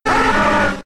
Cri de Salamèche K.O. dans Pokémon X et Y.